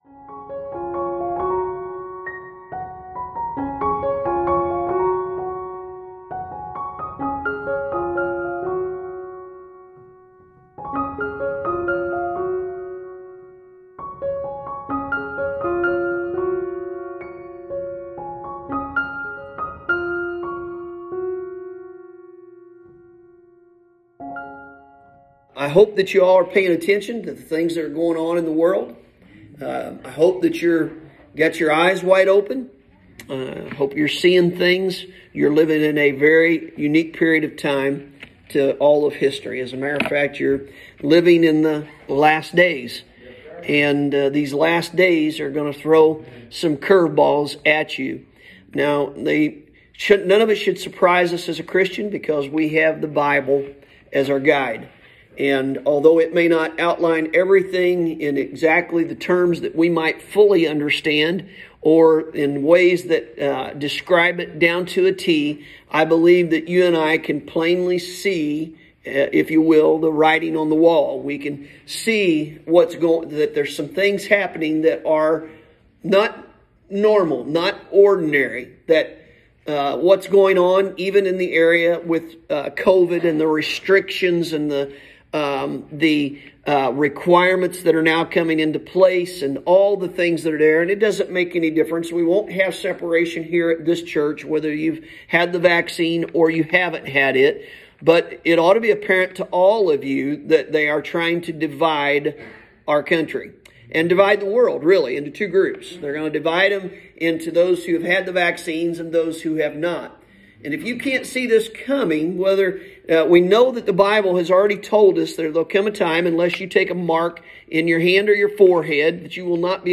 Sunday Morning – September 12th, 2021